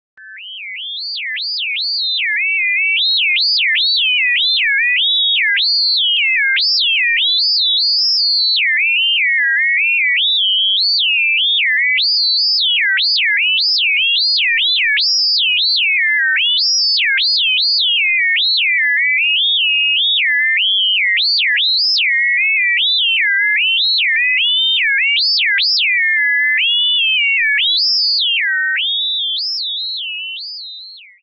Can you hear the melody that I hid in this horrible, horrible sound ?
CombinationTones.ogg